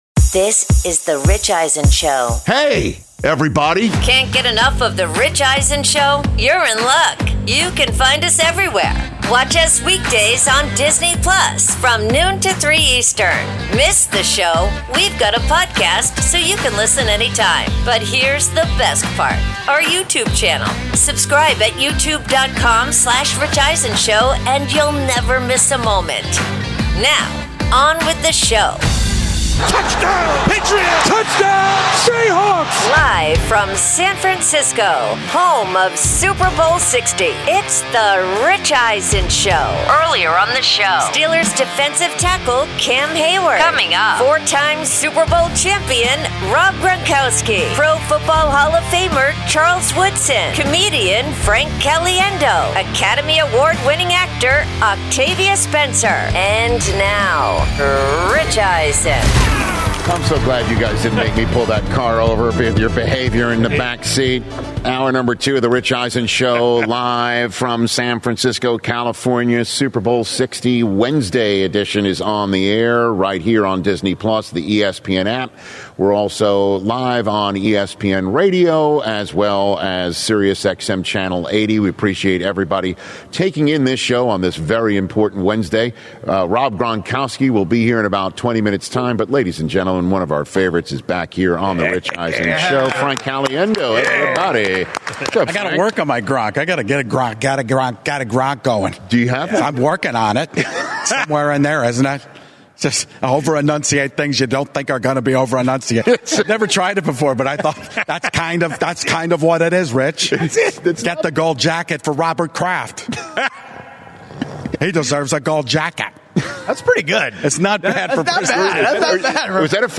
Live from Super Bowl LX in San Francisco where comedian Frank Caliendo visits the set and does his hilarious, spot-on impressions of Rob Gronkowski, Billy Bob Thornton, Jerry Jones, Jay Glazer, Jon Gruden, John Madden, Morgan Freeman, Al Pacino and more.